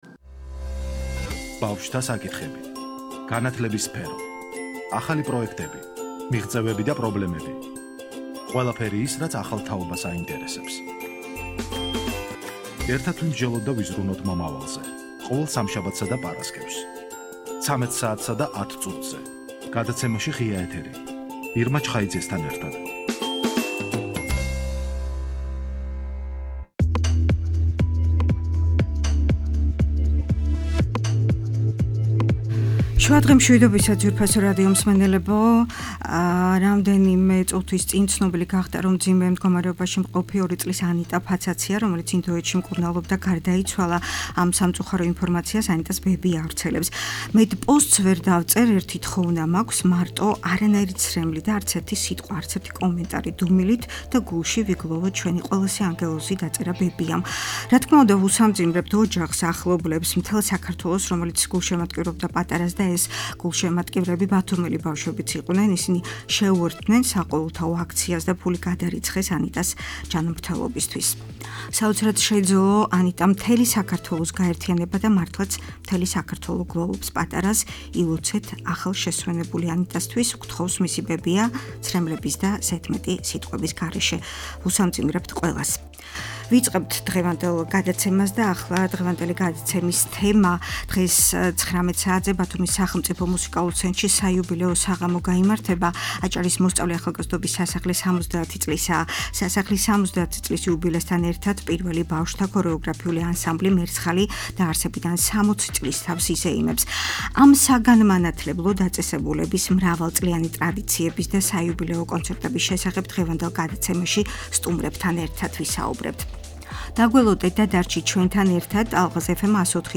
საიუბილეო საღამო-აჭარის მოსწავლე ახალგაზრდობის სასახლის 70 წლიანი ისტორია და პირველი ბავშვთა ქორეოგრაფიული ანსამბლი ,,მერცხალი’’